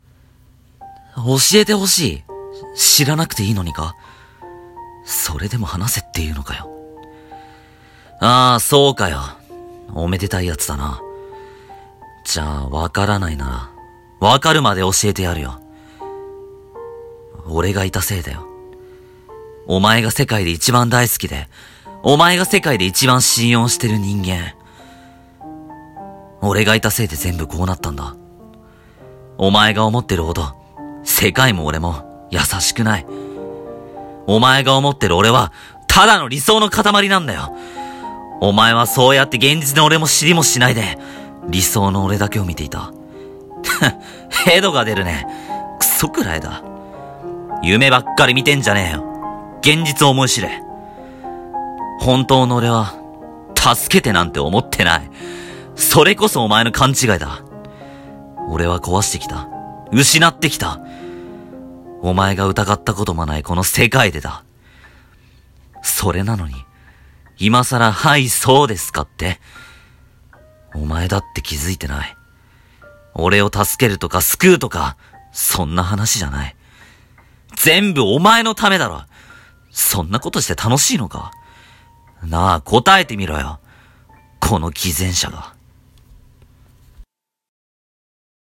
【一人声劇】理解